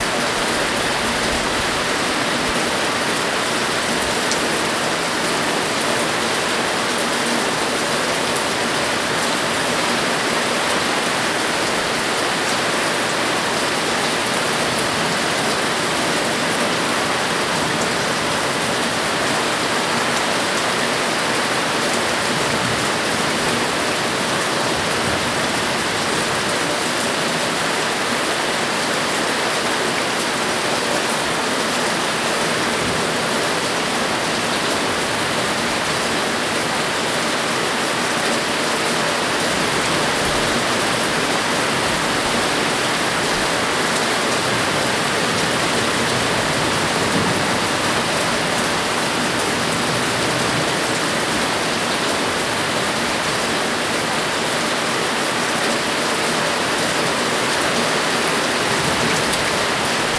city_rain.wav